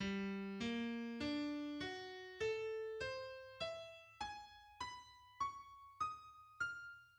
Here is Berg's tone row:
Although this contains all twelve notes of the chromatic scale, there is a strong tonal undercurrent: the first three notes of the row make up a G minor triad; notes three to five are a D major triad; notes five to seven are an A minor triad; notes seven to nine are an E major triad;